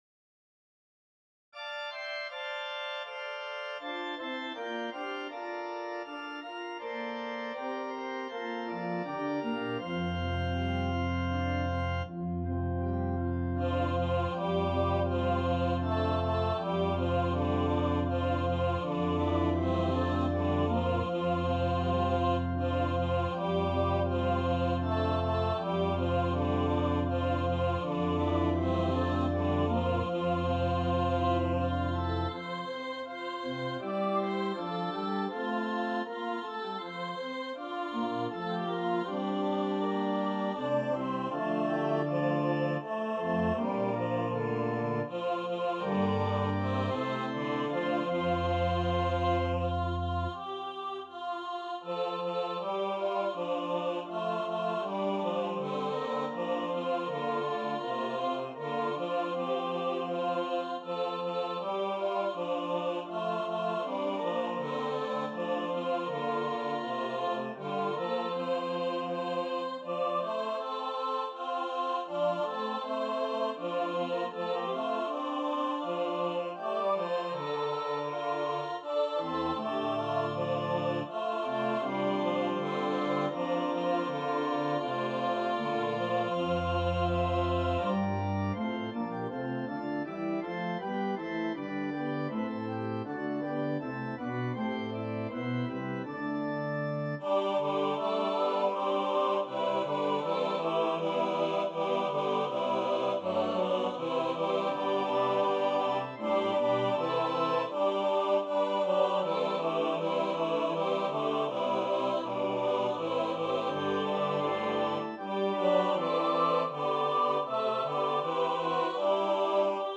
• Music Type: Choral
• Voicing: SATB
• Accompaniment: Organ